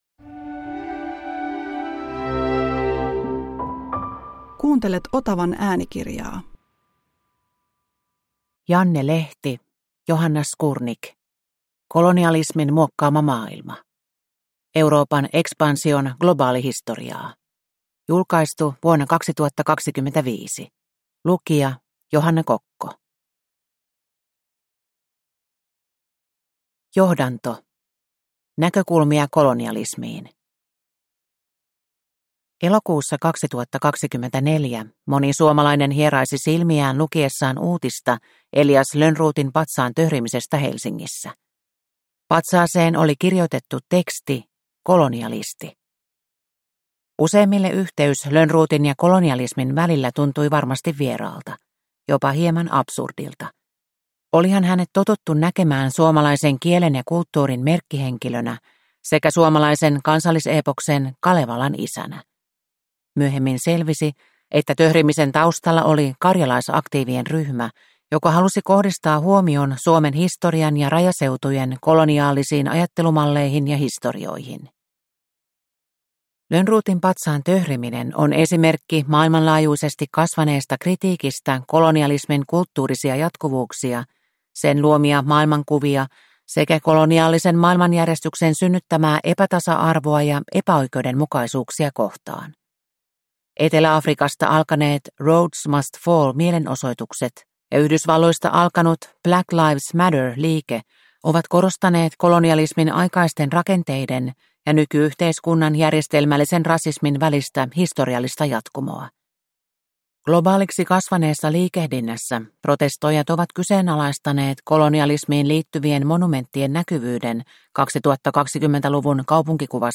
Kolonialismin muokkaama maailma – Ljudbok